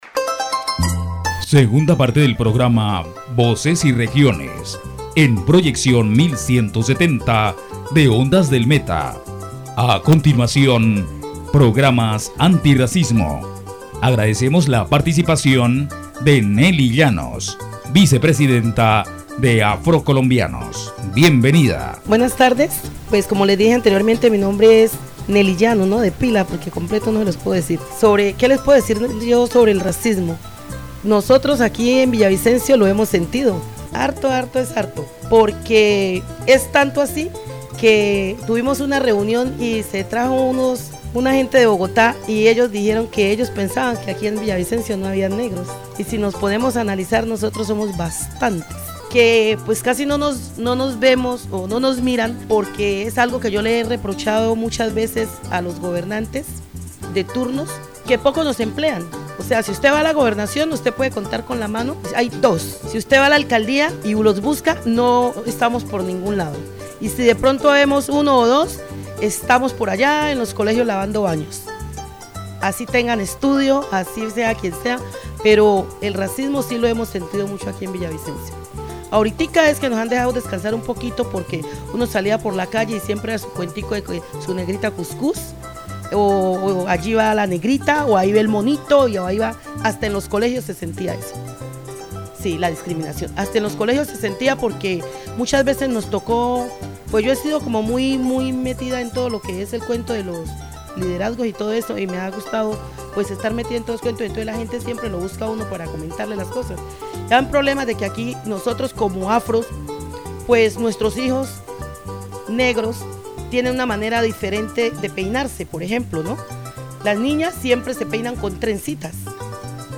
The radio program "Voices and Regions" delves into the experiences of Afro-Colombians in Villavicencio, with special emphasis on issues of identity, discrimination and preservation of their cultural heritage. The debate highlights the challenges this community faces, such as lack of representation in government, limited economic opportunities, and racial discrimination. Key topics discussed are: racial discrimination, cultural preservation, economic empowerment, community organizing, and government policies.